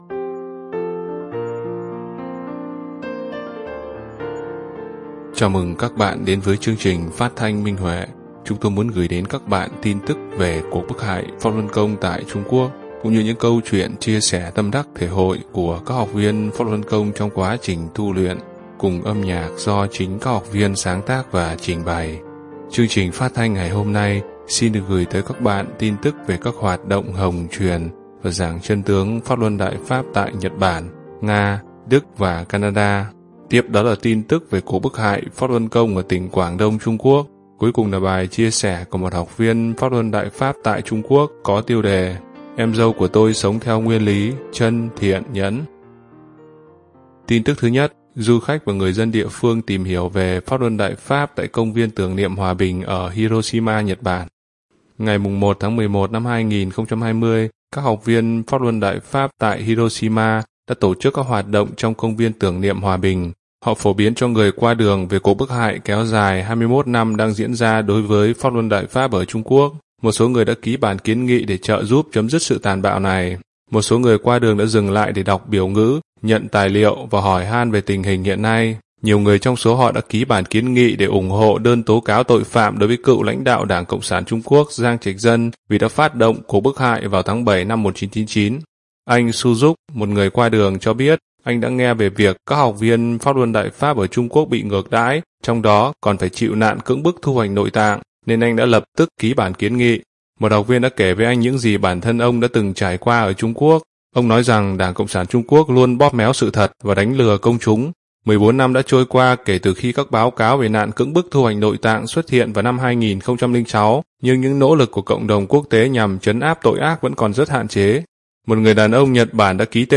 Chào mừng các bạn đến với chương trình phát thanh Minh Huệ. Chúng tôi muốn gửi đến các bạn tin tức về cuộc bức hại Pháp Luân Công tại Trung Quốc cũng như những câu chuyện chia sẻ tâm đắc thể hội của các học viên Pháp Luân Công trong quá trình tu luyện, cùng âm nhạc do chính các học viên sáng tác và trình bày.